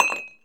Coffee Cup Drop Sound
household